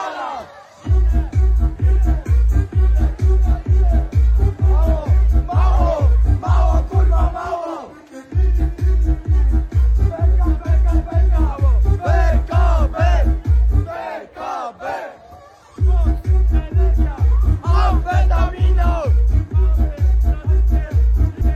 poszukuję tej nutki - Muzyka elektroniczna
wcześniej tam śpiewała dziewczyna i było takie ty ty ty ty tururururutu